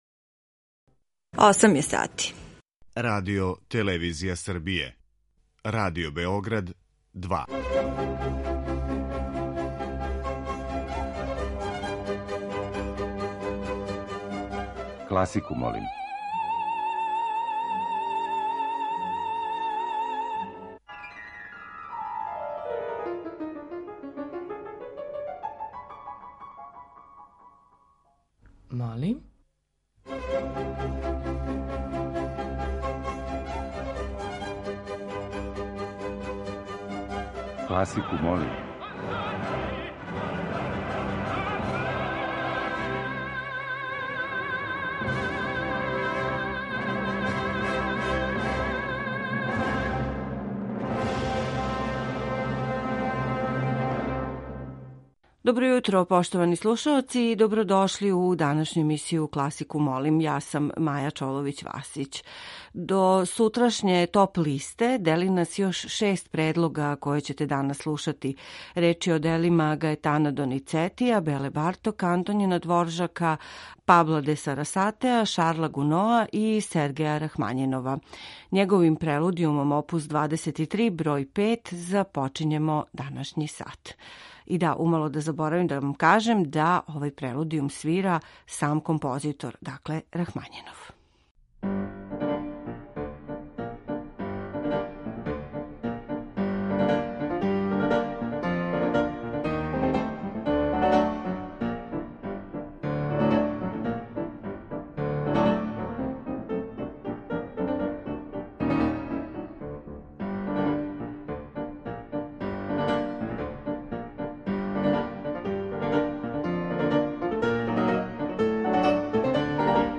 Међу њима је и неколико духовитих и шаљивих примера композиторске и извођачке креације, као део овонедељне теме, а слушаћете и четири снимка са концерата одржаних у оквиру 55. фестивала „Мокрањчеви дани".